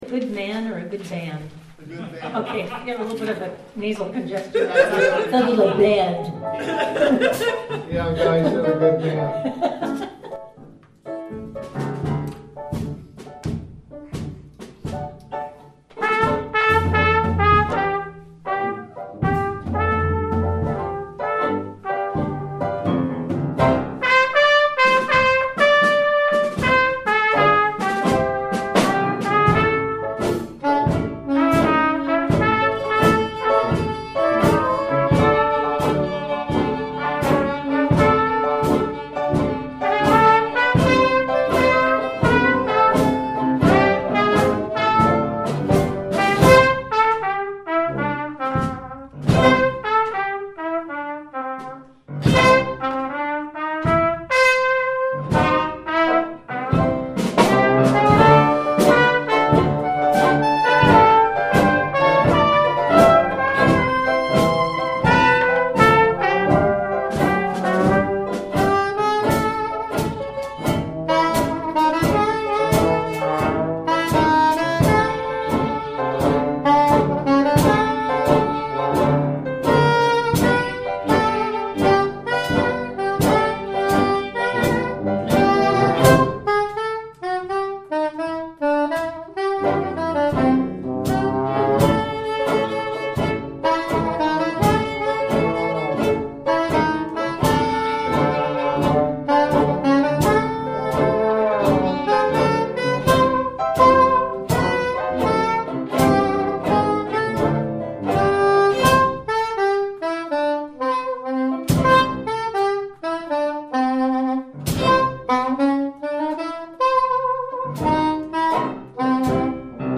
Piano
Drums
Tuba
Banjo
Trombone
SETTING: Jam sessions and practice sessions. There are no studio recordings in this list.
We added as much improv as appropriate.